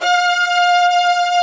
STR VIOLAS06.wav